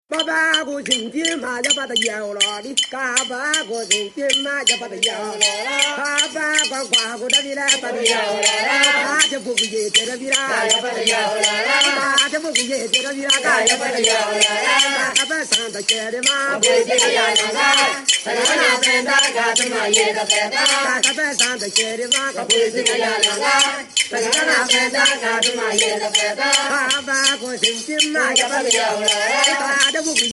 Ce tam-tam accompagné de flûtes aux notes mélodieuses qui vous emportent, sont exécutées par des jeunes filles et garçons lors des réjouissances. Elles vous annoncent le début de la saison sèche.